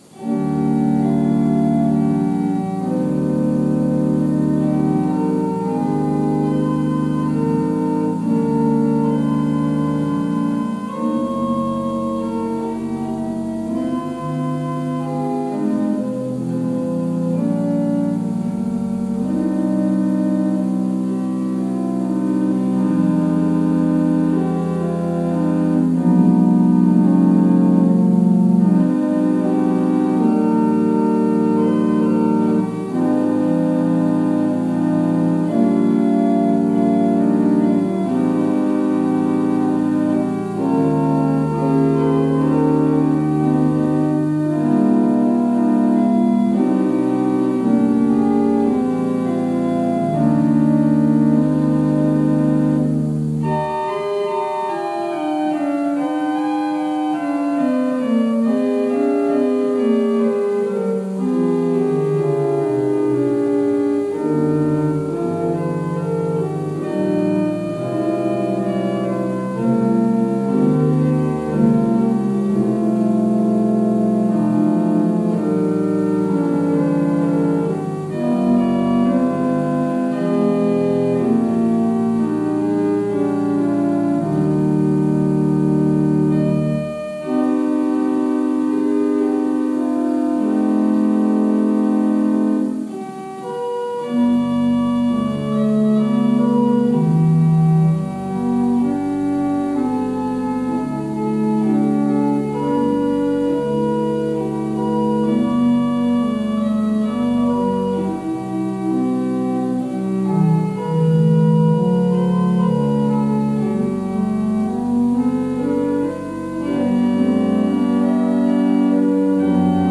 Worship Service April 11, 2021 | First Baptist Church, Malden, Massachusetts
Sermon: STRENGTH’S WEAKNESS